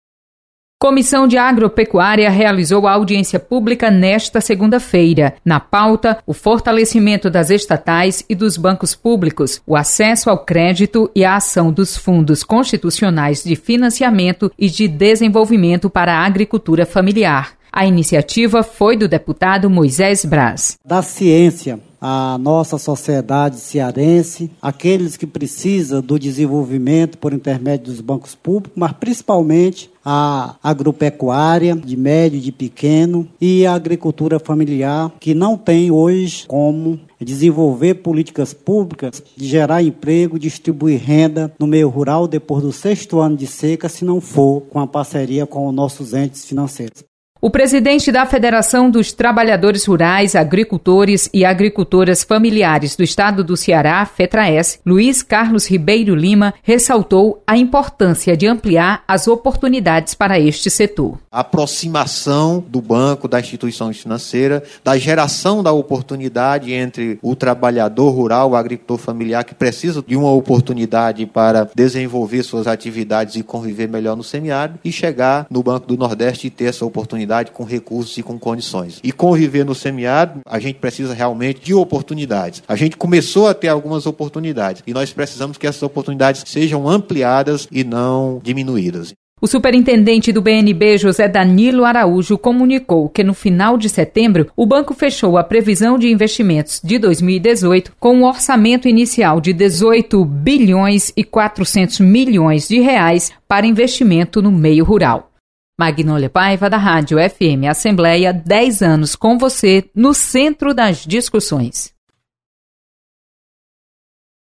Audiência discute fortalecimento das estatais. Repórter